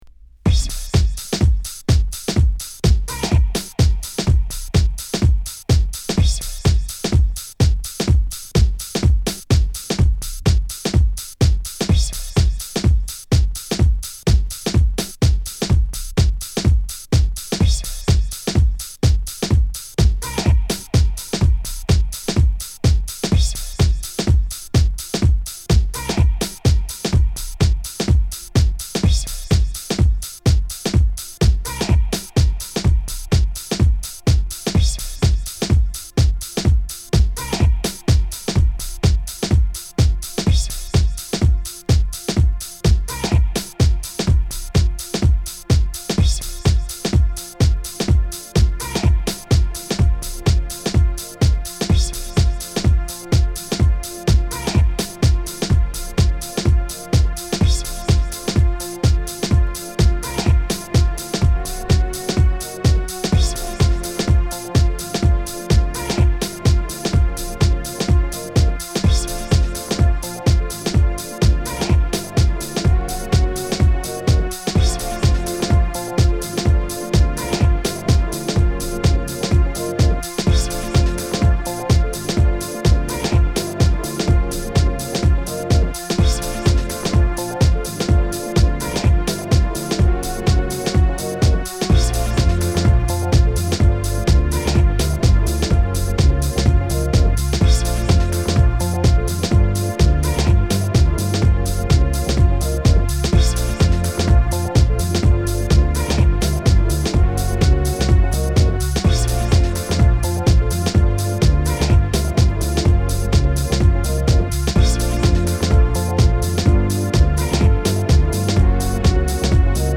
いつもの大熱唱歌い上げ系とは一線違うディープ・エレクトロ・ハウス！！ブリージンです。